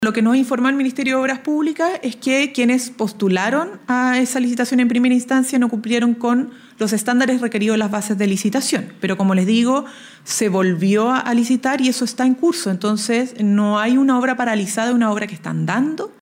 La ministra vocera de Gobierno, Camila Vallejo, explicó que ninguno de los oferentes cumplió con los requisitos para su realización.